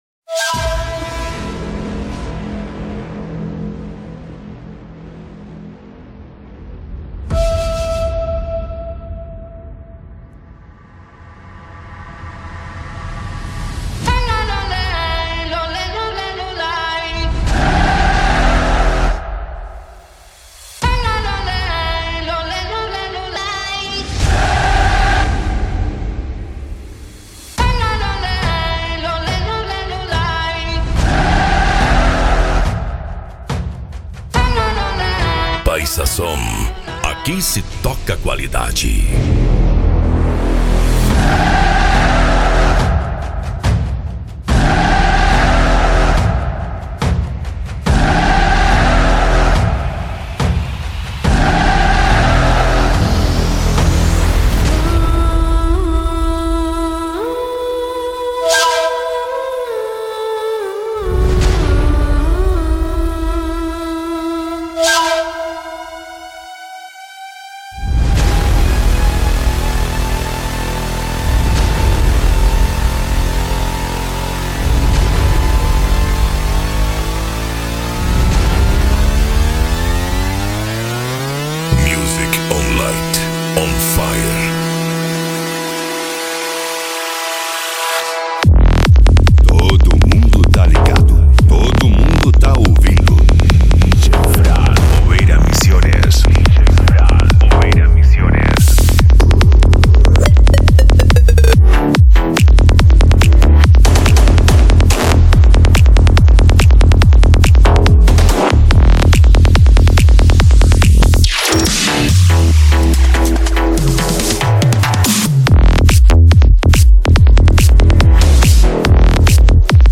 Psy Trance
Racha De Som
Remix